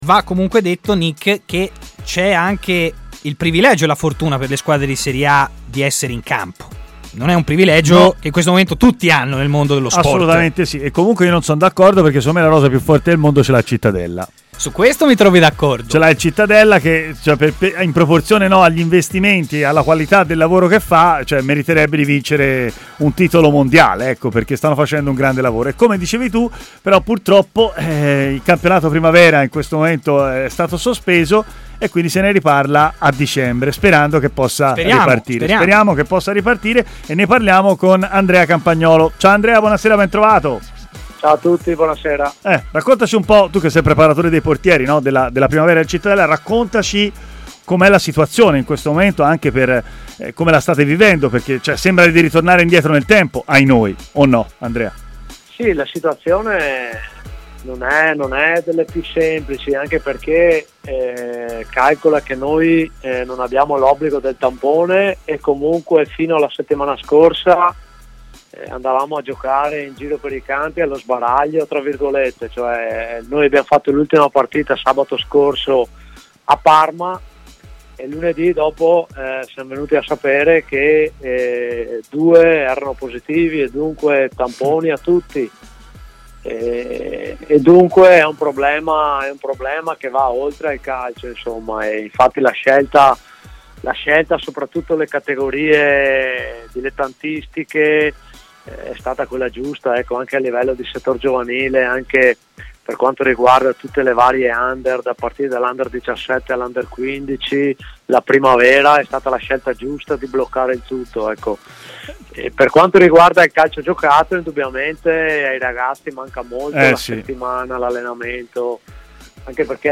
ha parlato a Stadio Aperto, trasmissione di TMW Radio